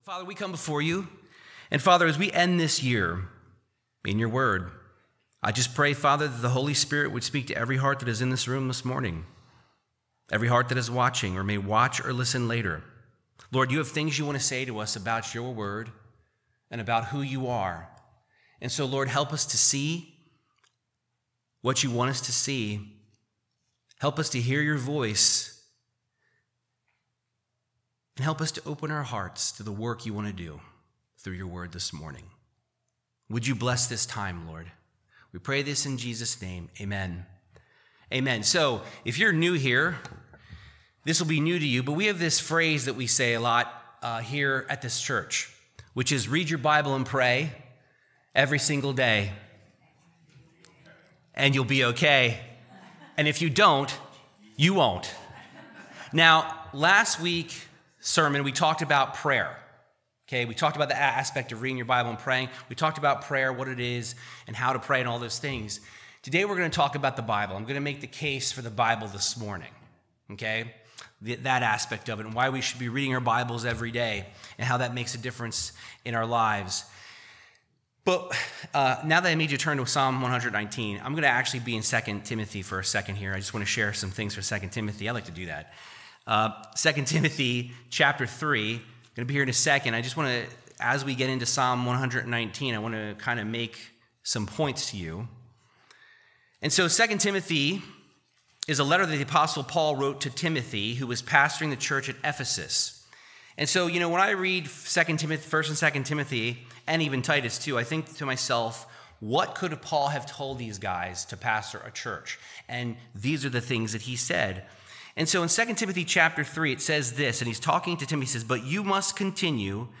Bible Teaching through Psalm 119 emphasizing the importance of Gods word in the life of a believer.